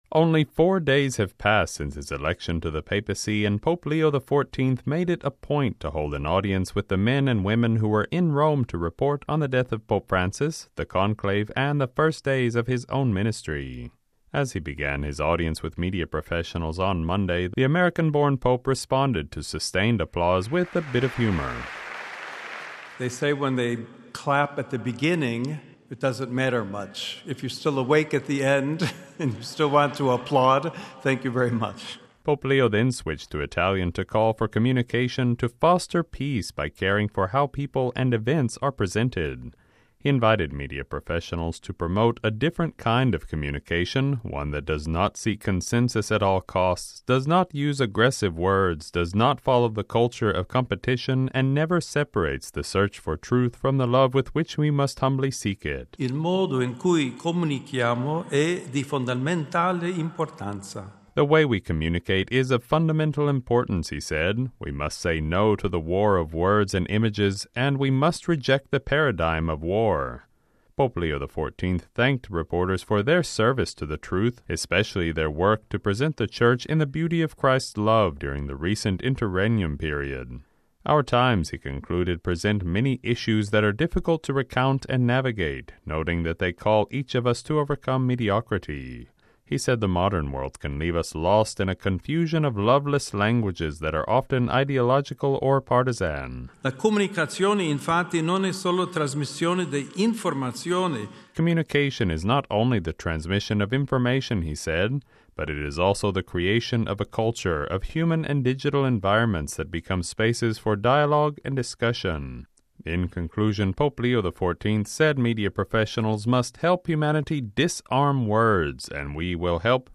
El lunes se reunió con profesionales de los medios de comunicación en el Aula Pablo VI del Vaticano y agradeció a los periodistas en italiano por su incansable trabajo durante estas intensas semanas.